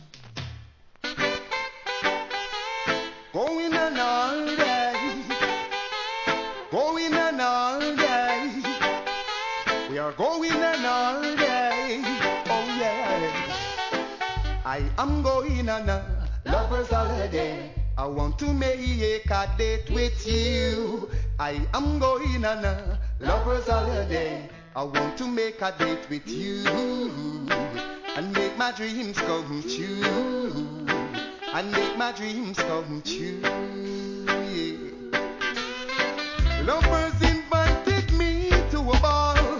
REGGAE
優しく歌い上げた1998年リリース!!